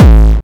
VEC3 Bassdrums Dirty 08.wav